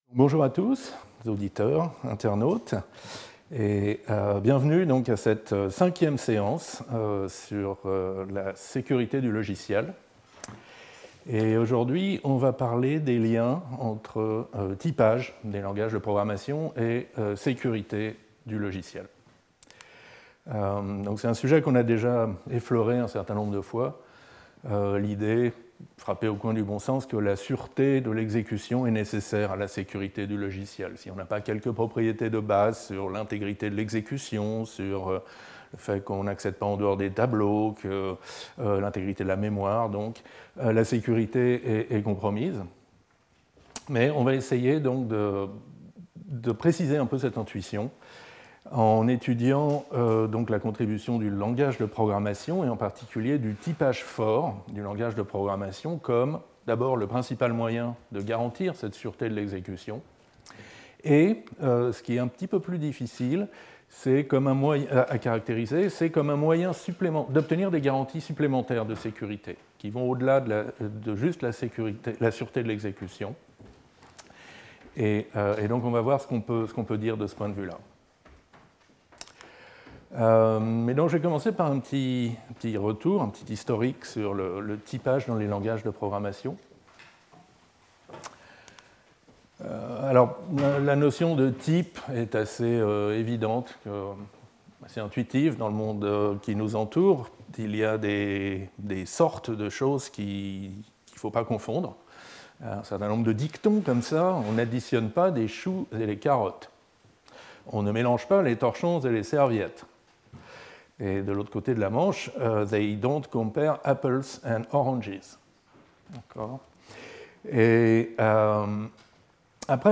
Intervenant(s) Xavier Leroy Professeur du Collège de France